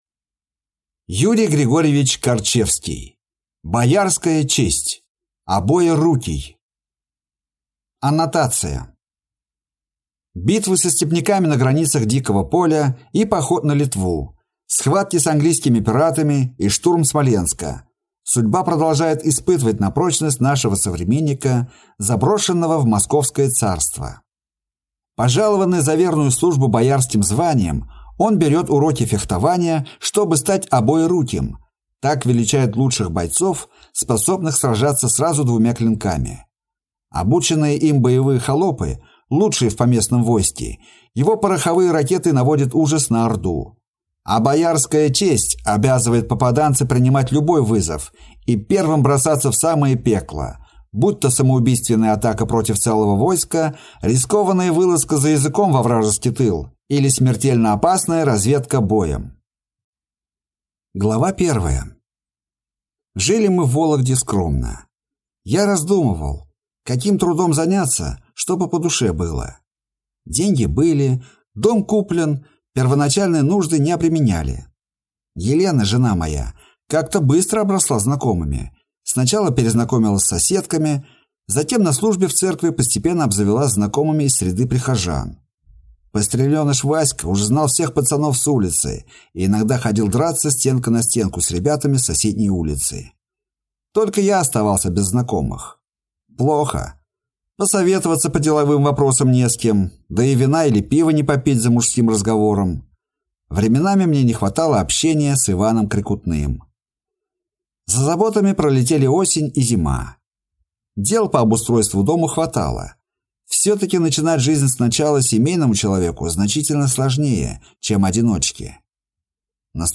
Аудиокнига Боярская честь.